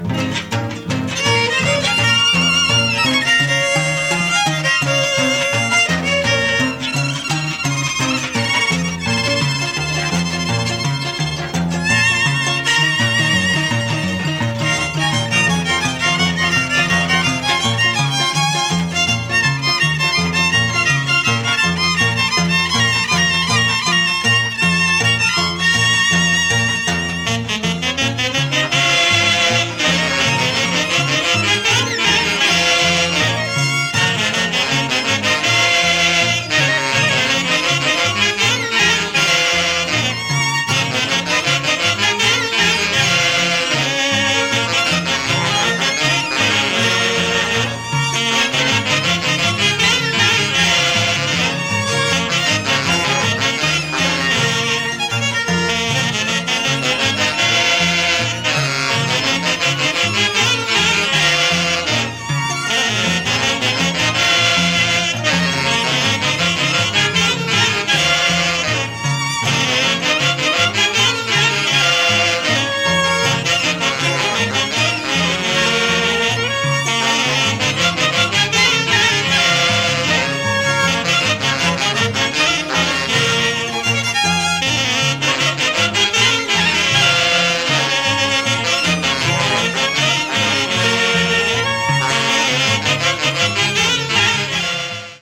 狂騒的なフォルクローレ！ペルーのワイノ！